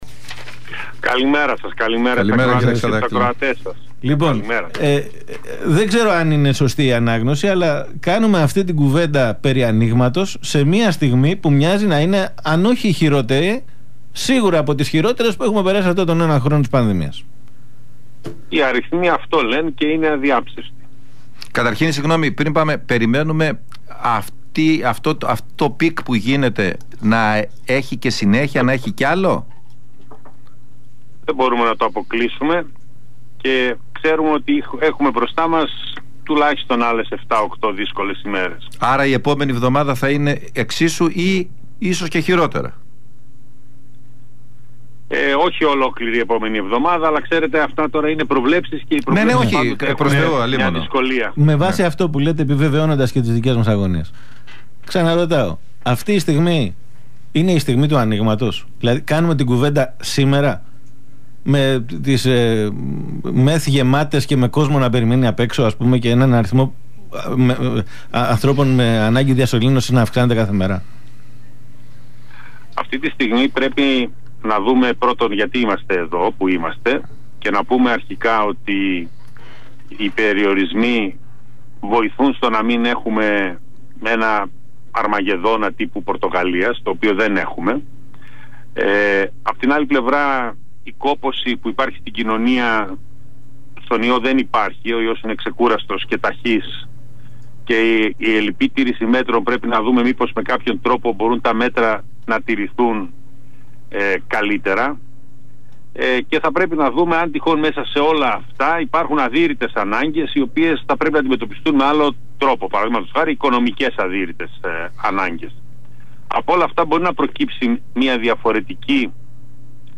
μιλώντας σήμερα στον Realfm 97,8